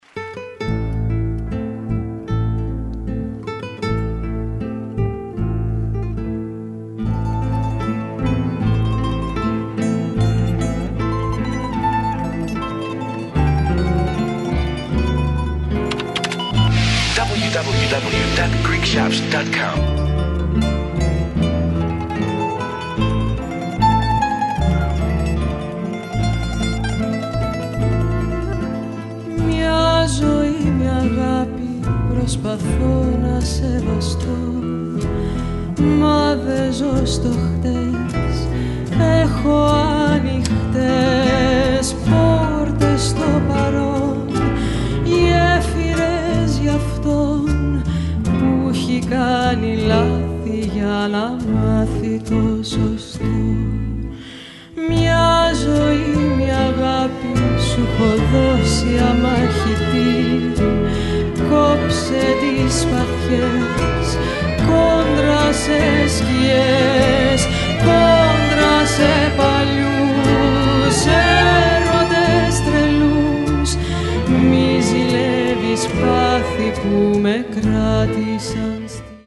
A live performance